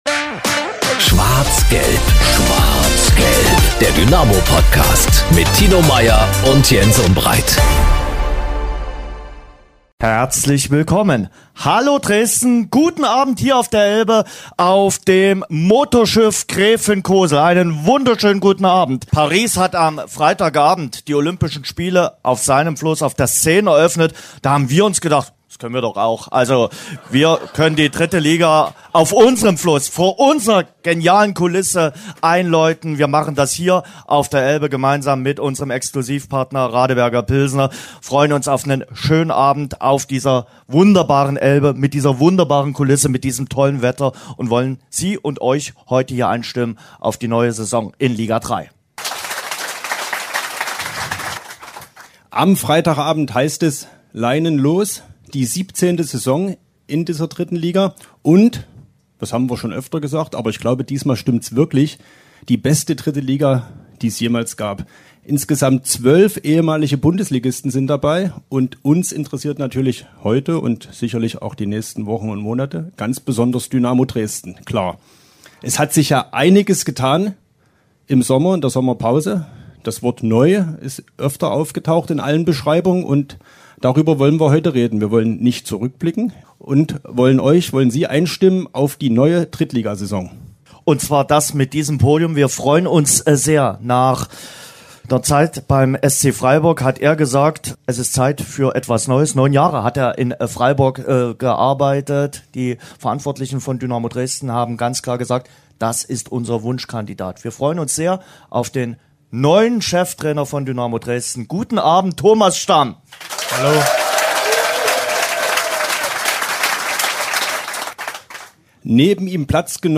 In unserem Live-Podcast blicken wir voraus auf die neue Spielzeit in der 3. Liga.